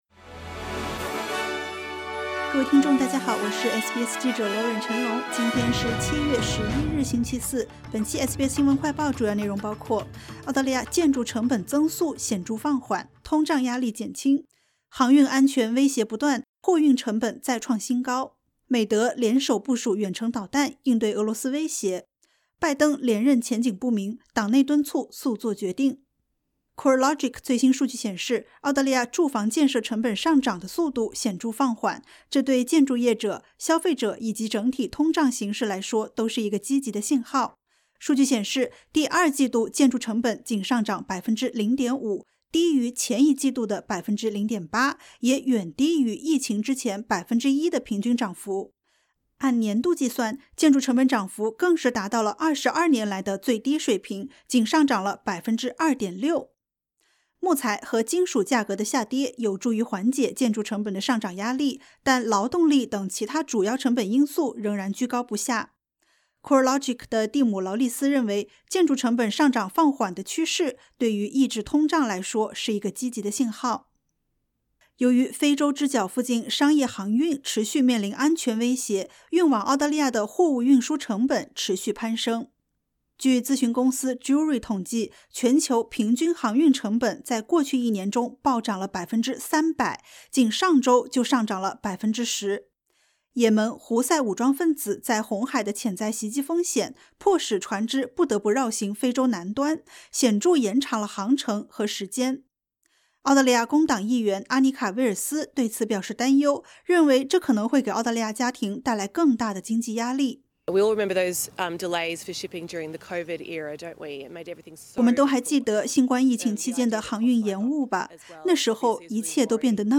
【SBS新闻快报】 澳建筑成本增速显著放缓 通胀压力减轻